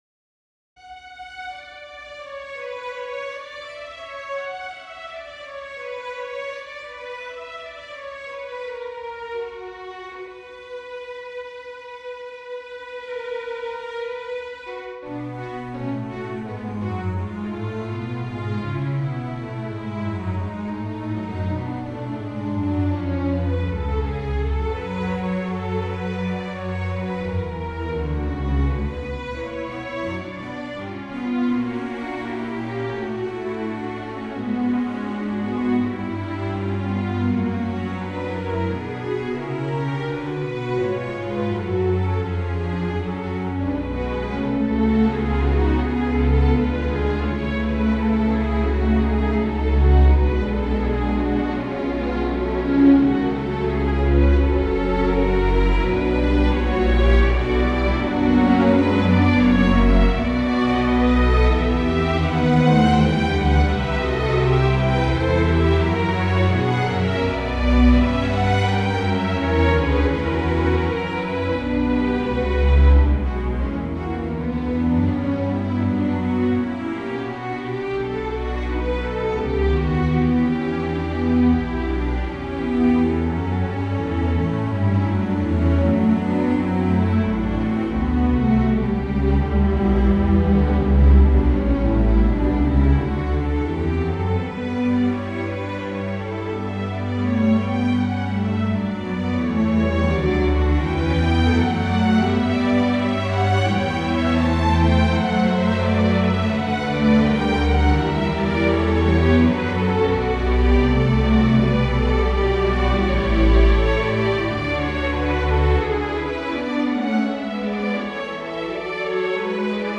Fugue in B minor - Arrangement for String Orchestra.